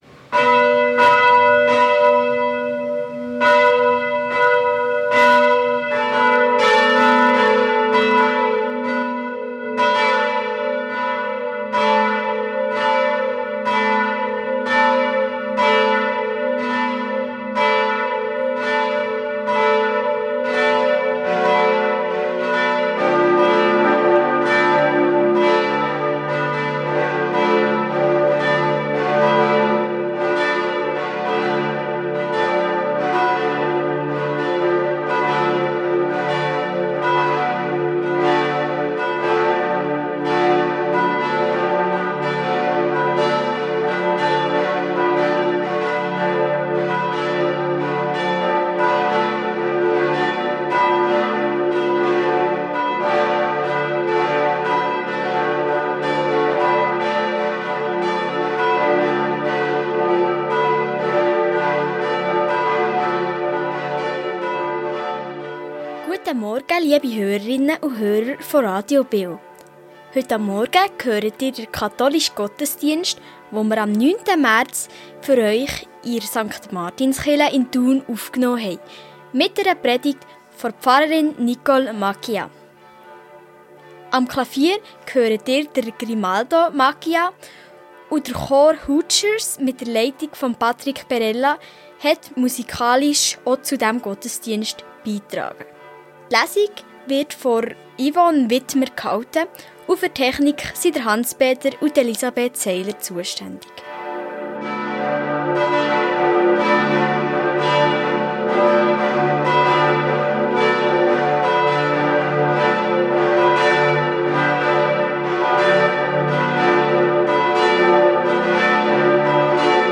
Katholische Kirche St. Martin Thun ~ Gottesdienst auf Radio BeO Podcast
BeO Gottesdienst